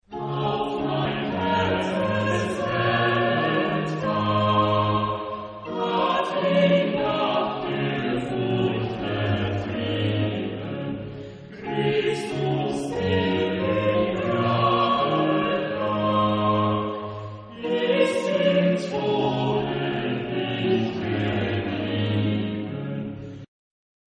Genre-Style-Forme : Choral ; Sacré
Type de choeur : SATB  (4 voix mixtes )
Instruments : Orgue (1) ad lib
Tonalité : do majeur